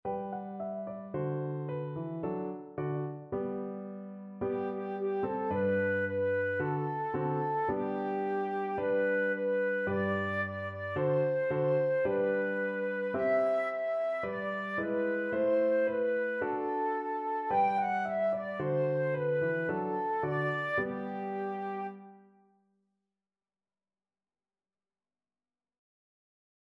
2/4 (View more 2/4 Music)
Fast =c.110
G5-G6